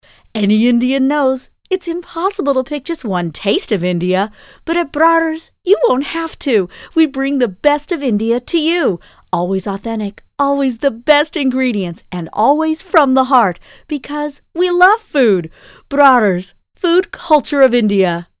I have a warm, upbeat professional voice that can enhance your project.
Sprechprobe: Werbung (Muttersprache):